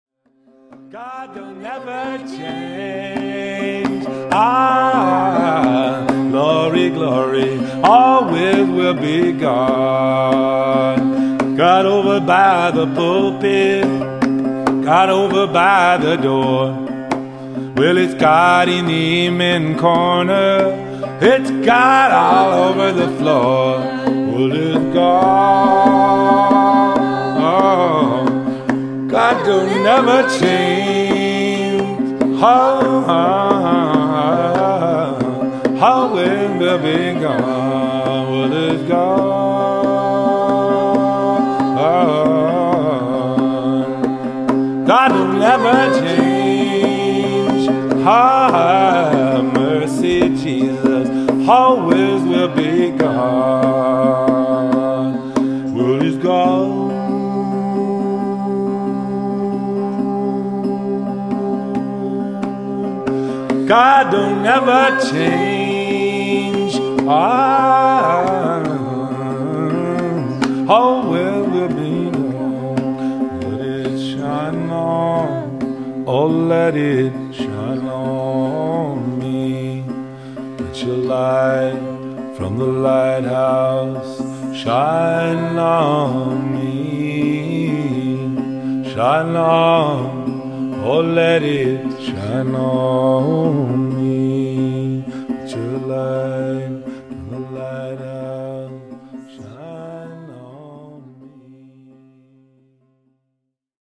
Live Kirtan Chanting CD